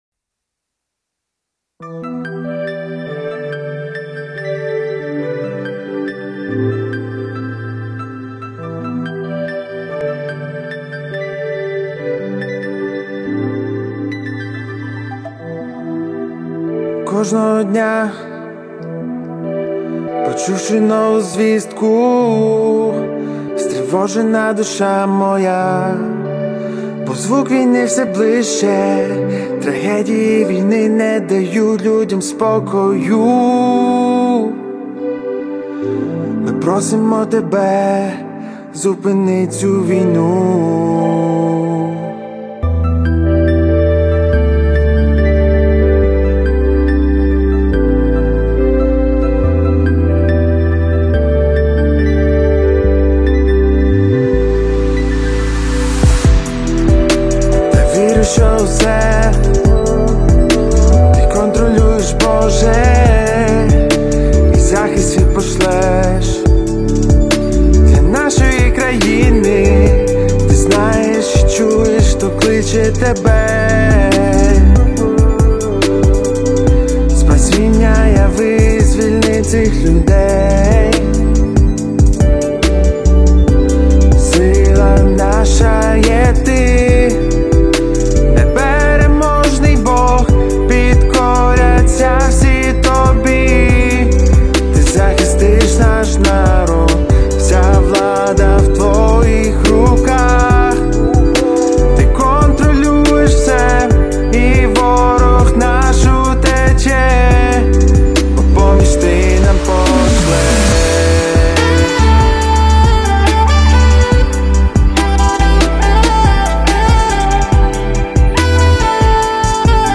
543 просмотра 212 прослушиваний 24 скачивания BPM: 141